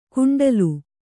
♪ kuṇḍalu